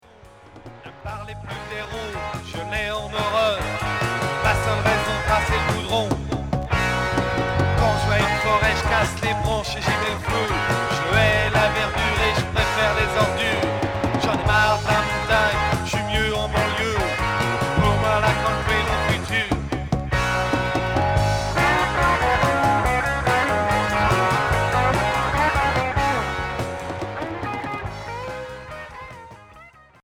Rock et progressif Unique 45t retour à l'accueil